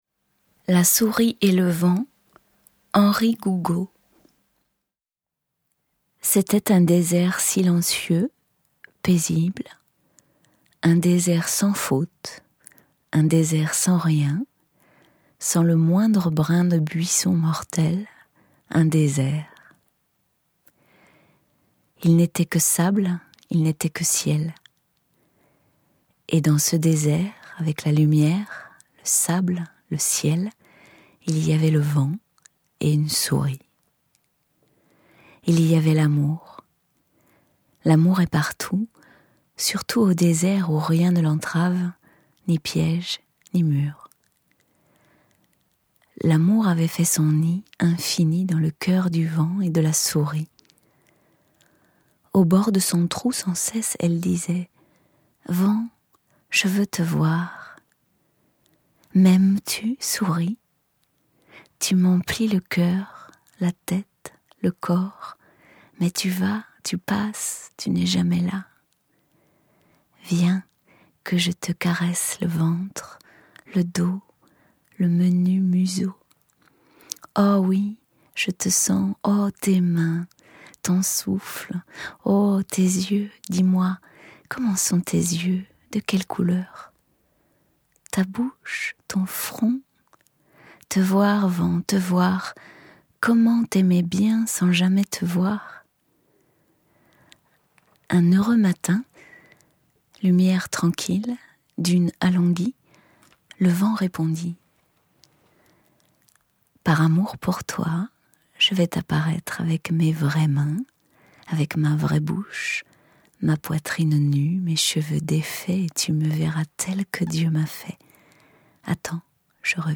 Lecture La souris et le vent / H. Gougaud